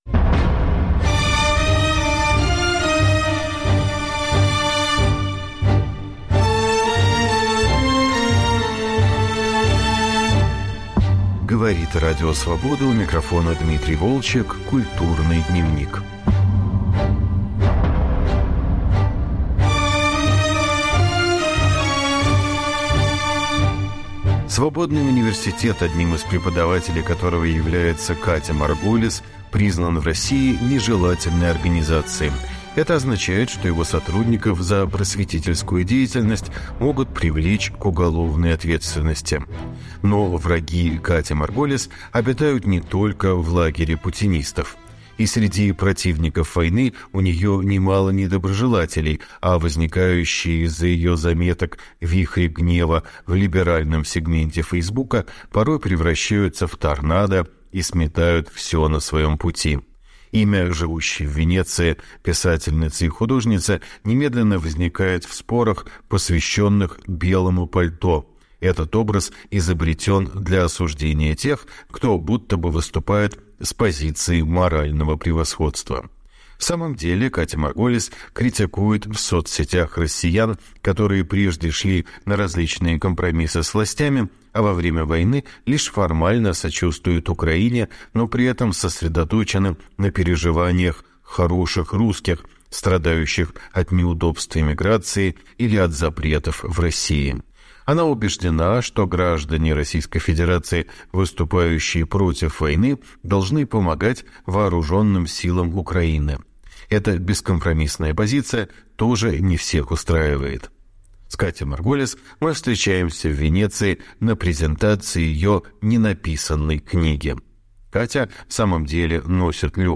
Разговор о "Ненаписанной книге", войне и "хороших русских"